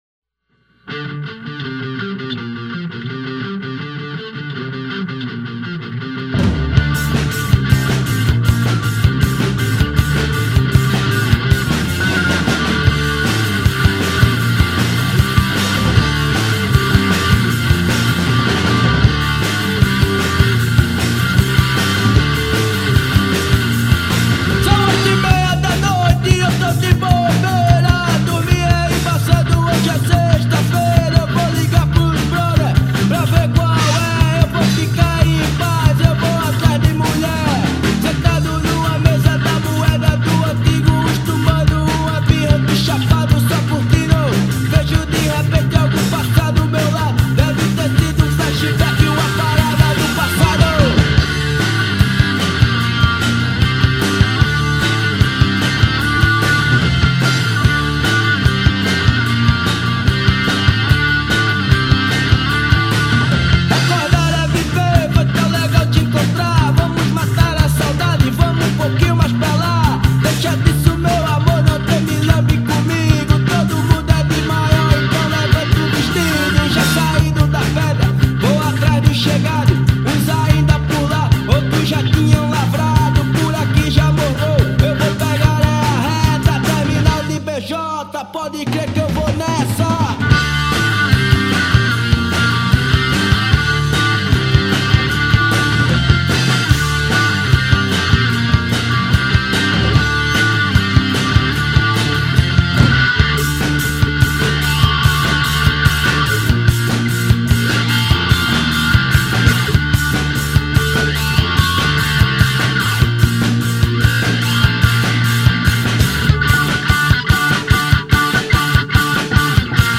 Baixo
Guitarra
Bateria
Vocal . Letras
Som direto, sem excesso, com peso e intenção.